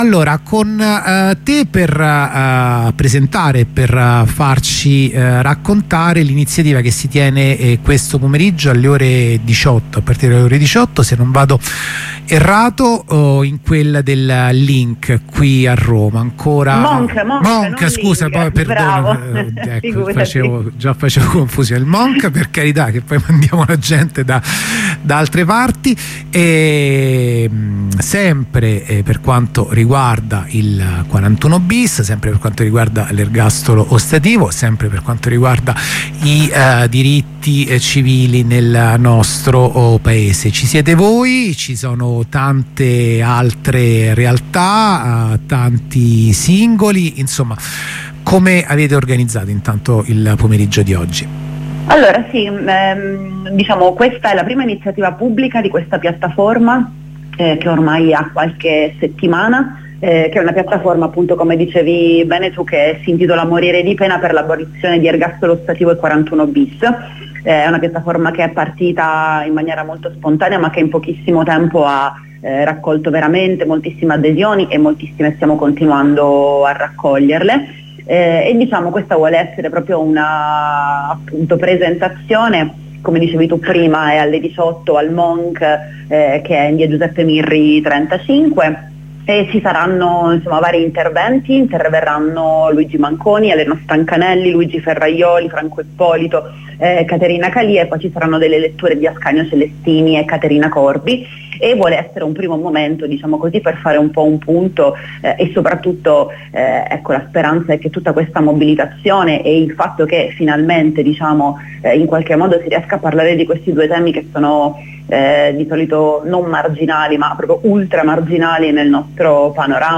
h 15:20 collegamento dalla nuova occupazione di bologna